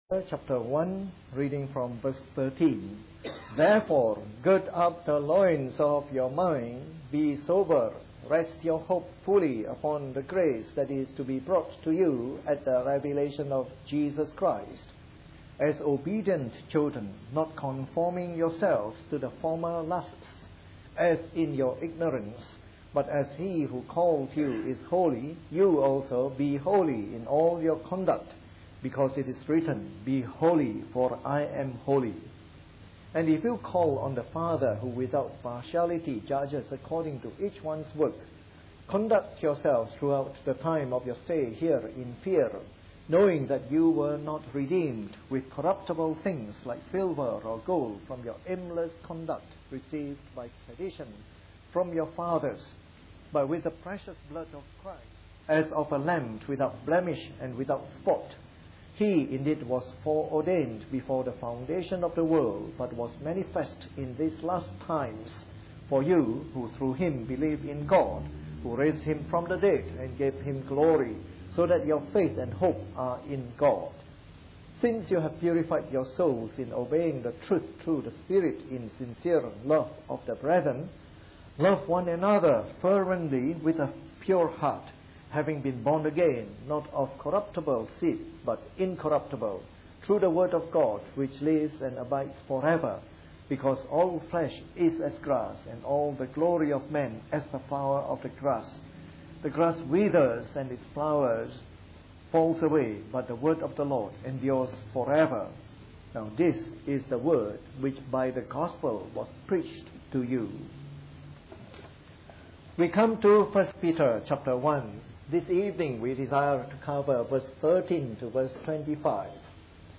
Part of our new series on “The Epistles of Peter” delivered in the Evening Service.